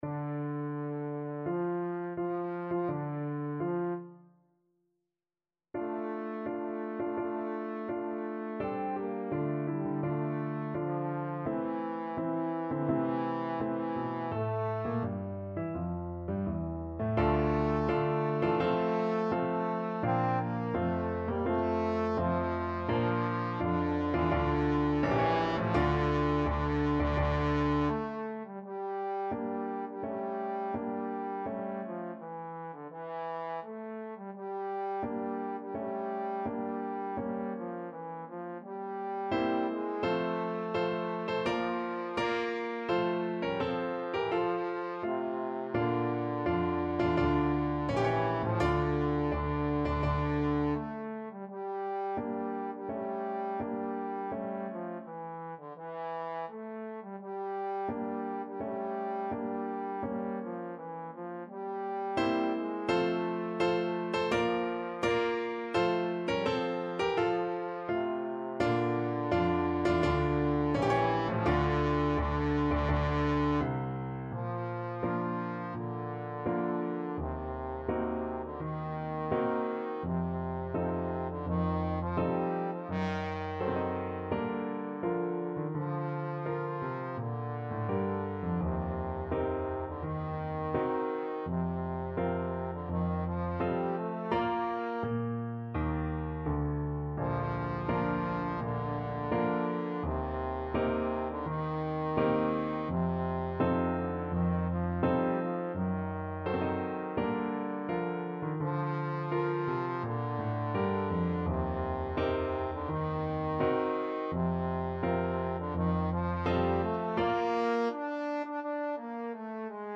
Trombone
D minor (Sounding Pitch) (View more D minor Music for Trombone )
4/4 (View more 4/4 Music)
Tempo di Marcia =84
A3-D5
Classical (View more Classical Trombone Music)